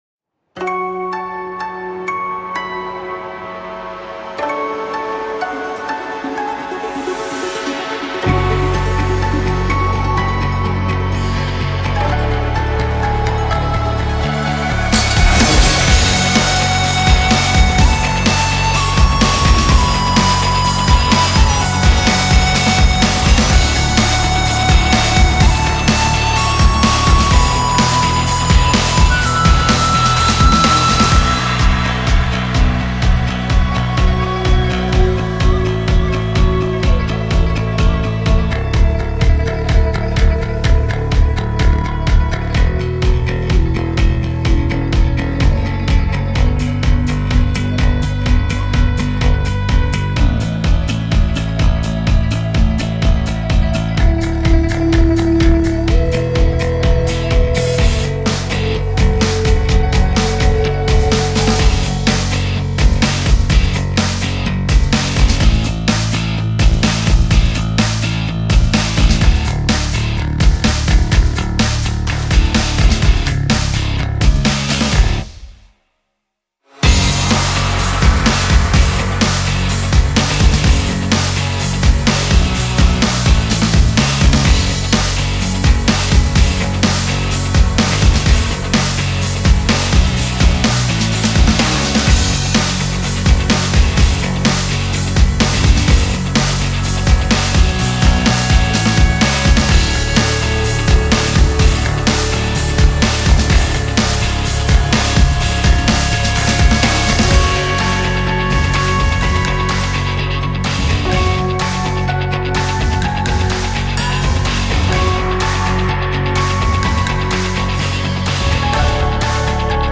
主题曲 主题曲伴奏 主题曲MV 主题曲伴奏MV